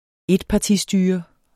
Udtale [ ˈedpɑˌti- ]